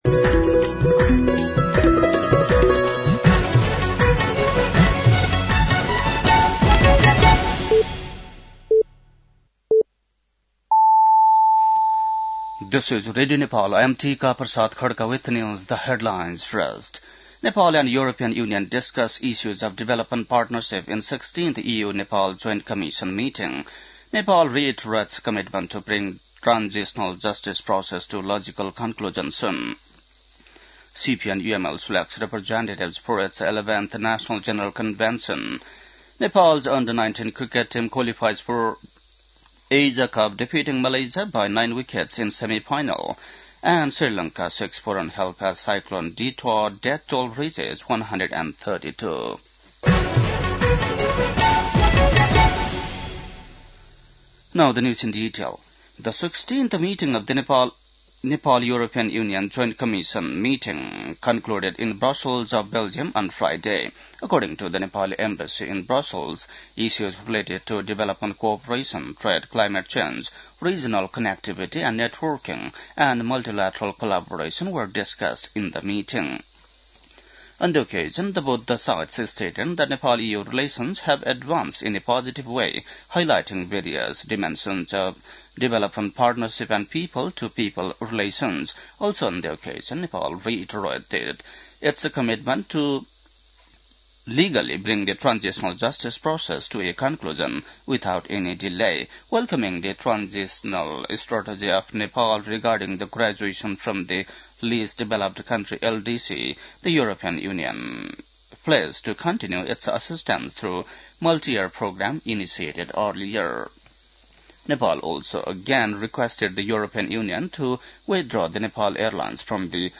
बेलुकी ८ बजेको अङ्ग्रेजी समाचार : १३ मंसिर , २०८२